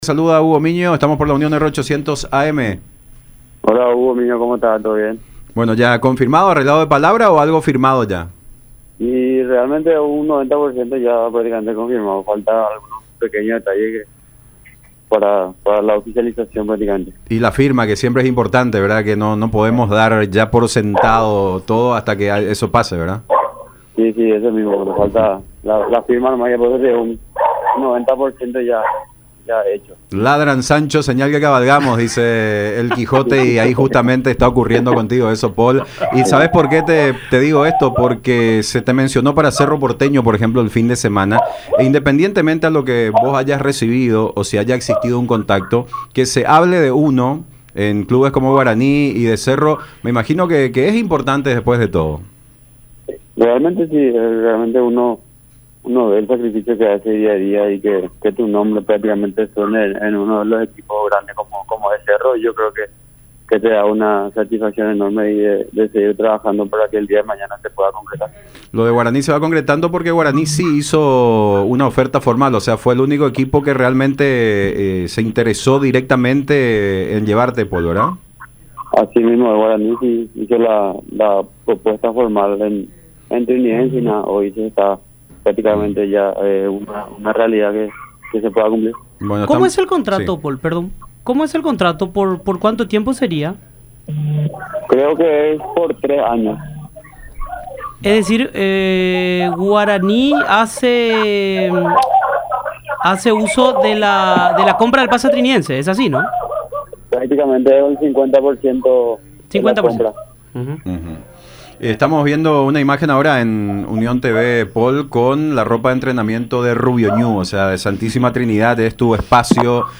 “En un 90% ya está confirmado, faltan algunos detalles nada más para la oficialización. Falta la firma nada más”, expresó en contacto con Fútbol Club, a través de radio la Unión y Unión TV.